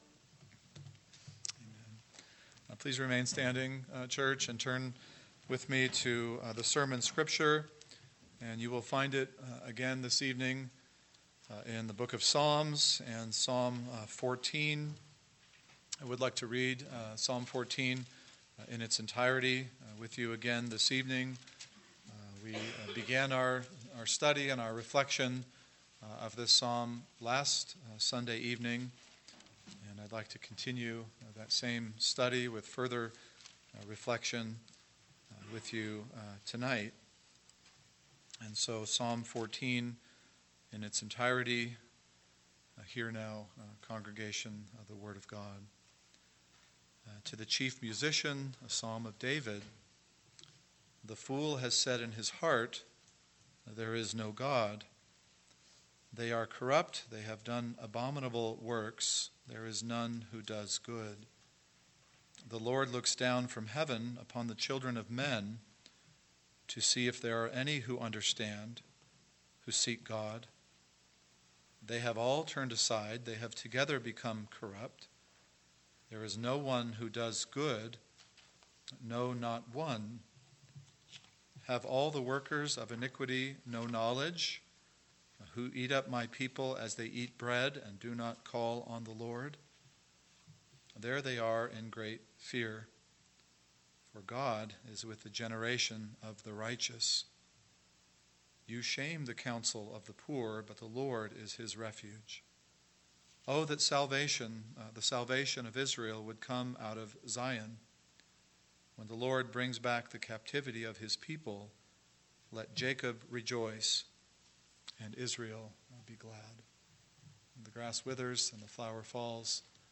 PM Sermon – 9/29/2019 – Psalm 14, part 2 – What Does the Fool Say?